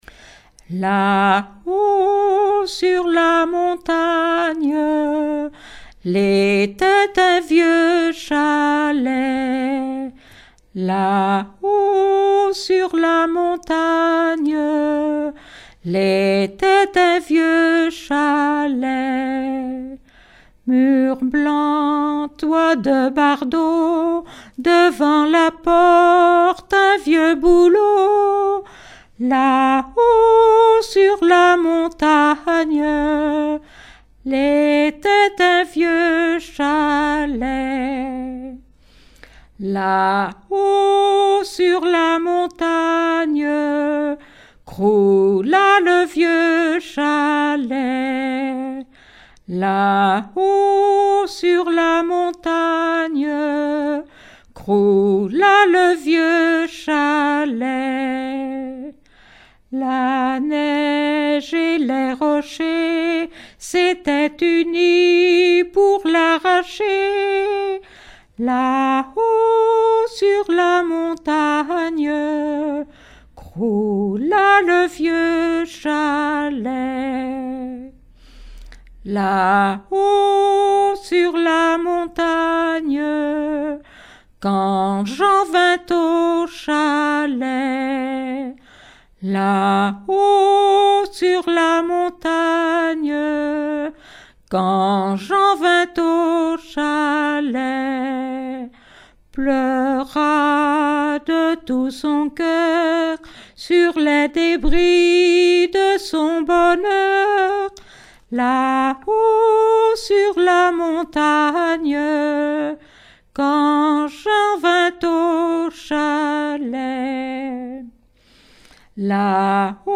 Chansons traditionnelles et populaires
Pièce musicale inédite